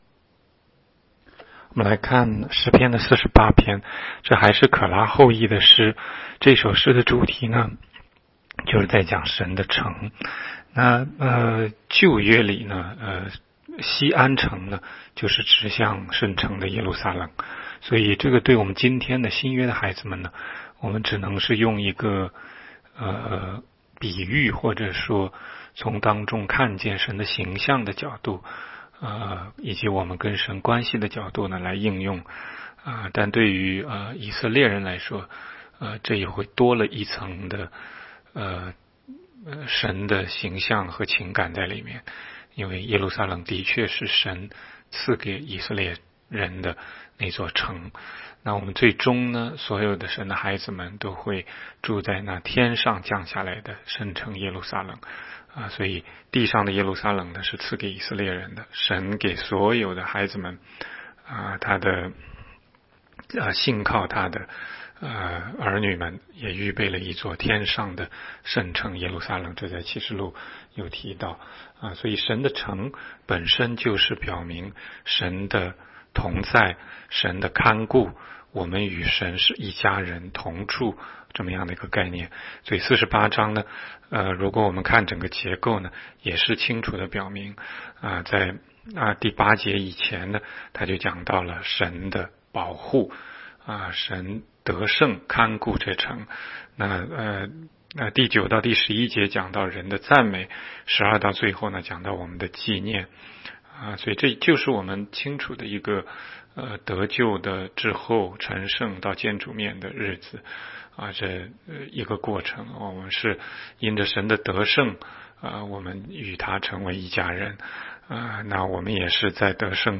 16街讲道录音 - 每日读经-《诗篇》48章